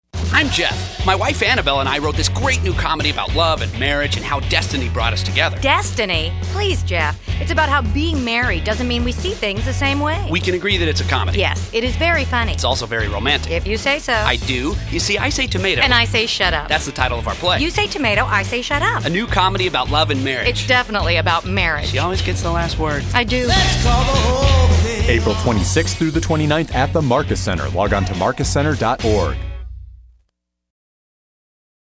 You Say Tomato, I say Shut Up Radio Commercial